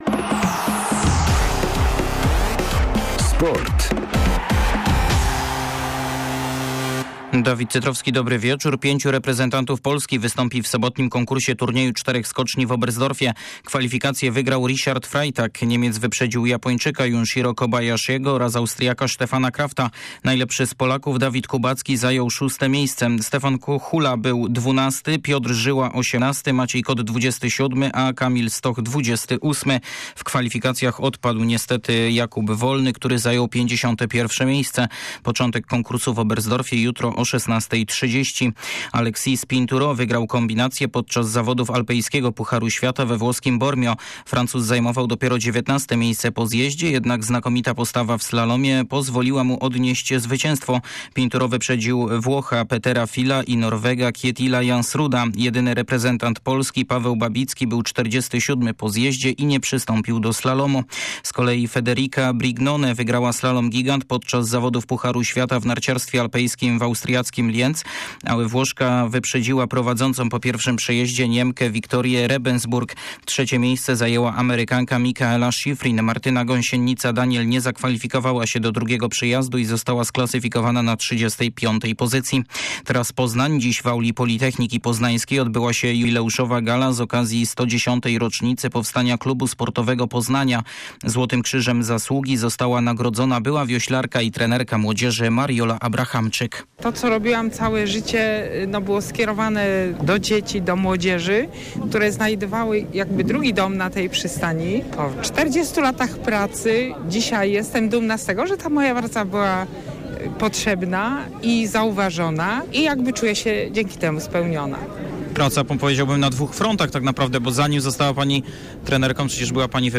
29.12 serwis sportowy godz. 19:05